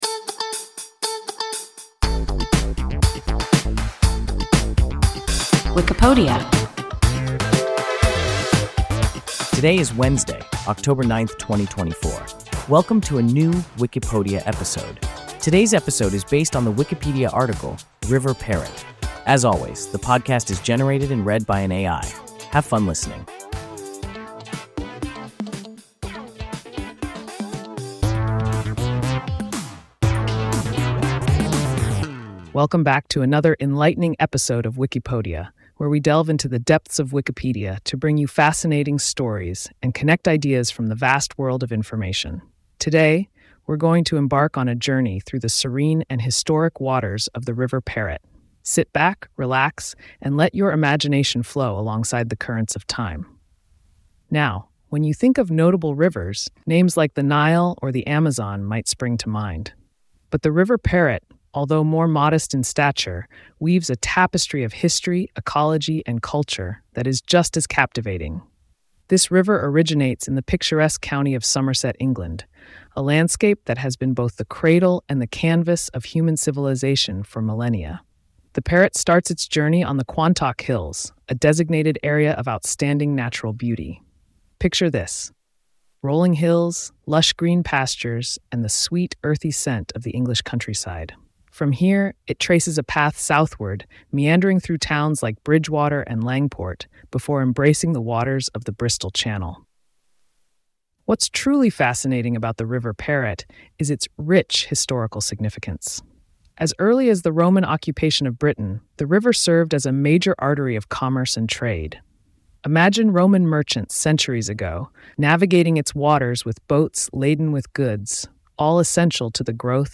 River Parrett – WIKIPODIA – ein KI Podcast